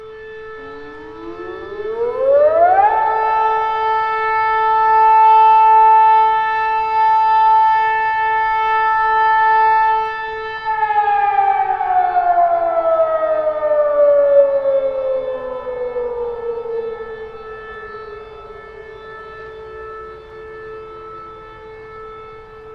Городская сирена предупреждения о террористической угрозе